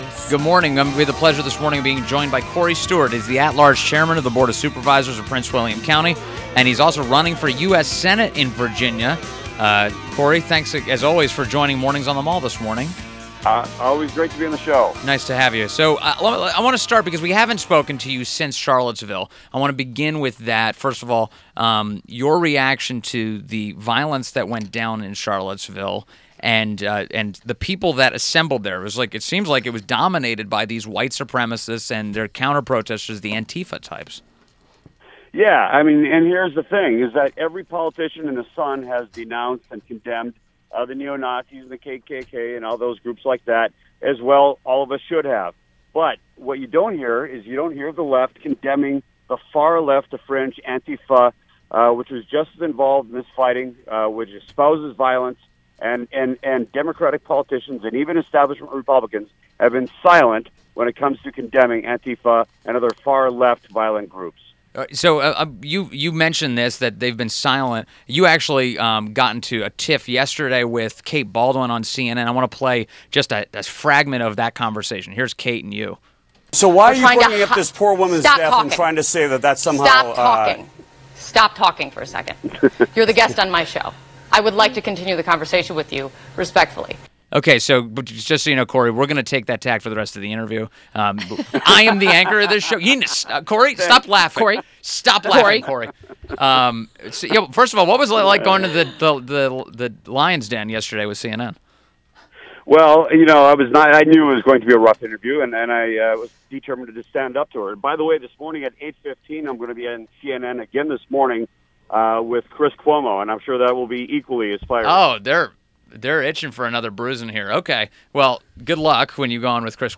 WMAL Interview - COREY STEWART - 08.18.17
INTERVIEW – COREY STEWART – At-Large Chairman of the Board of Supervisors of Prince William County, Virginia and is running for U.S. Senate in Virginia. Stewart has been a proponent of keeping confederate statues